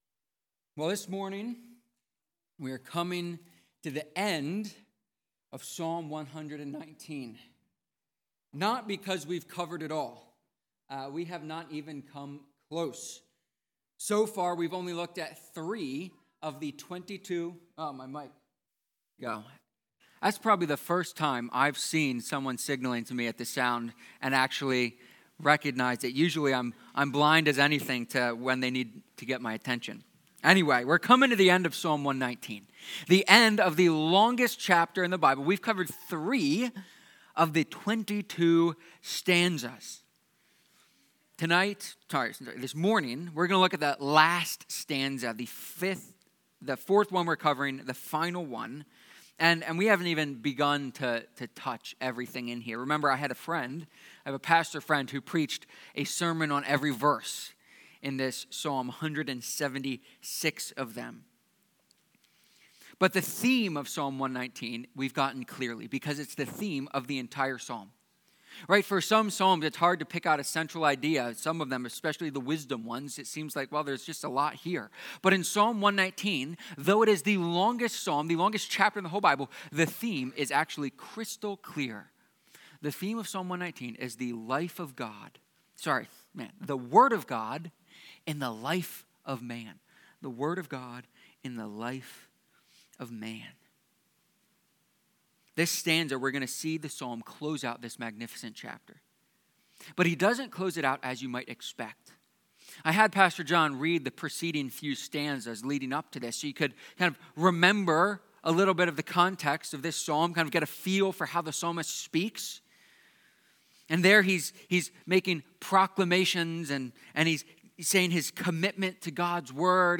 Psalm-119-sermon-4.mp3